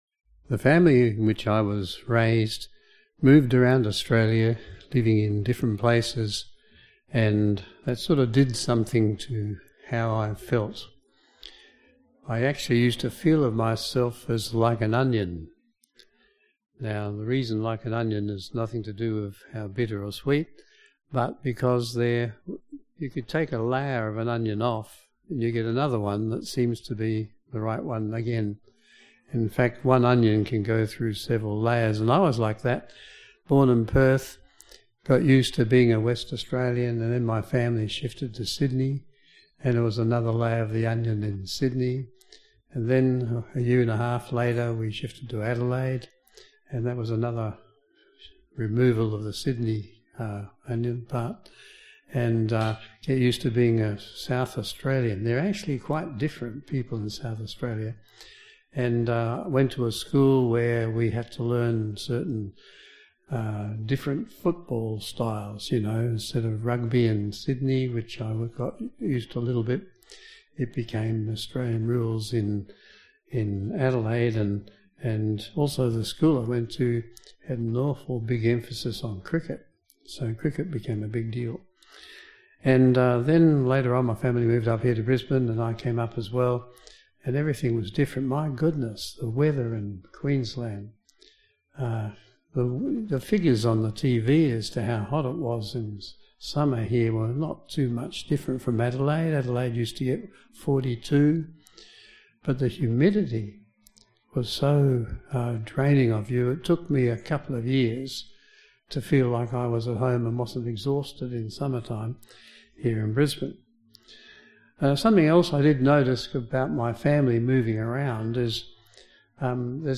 This sermon explores the human feeling of being at home in a new place. It draws parallels between these experiences and the Christian journey towards finding true rest and belongingness in Christ. Highlighting key Bible passages, the sermon emphasises Jesus’ preparation of resting places in heaven and His personal return to collect His followers.